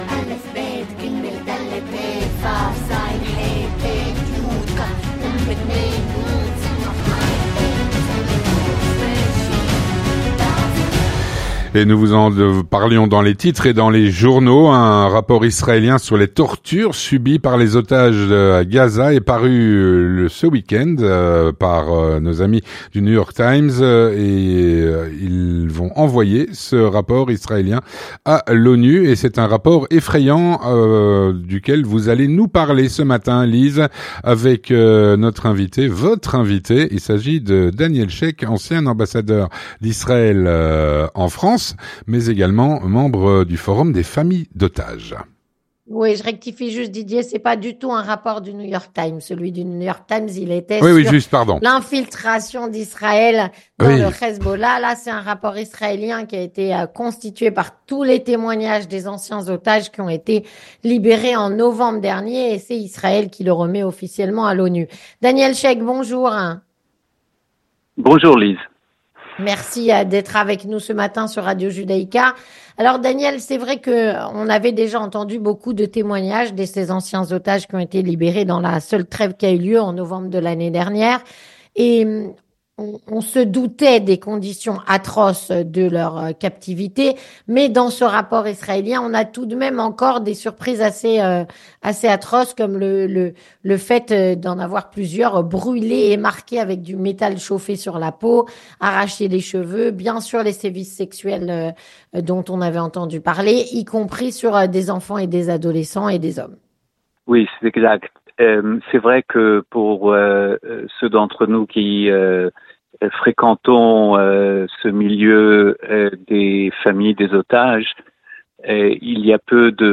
Avec Daniel Shek, ancien ambassadeur d'Israël en France et membre du Forum des familles d’otages.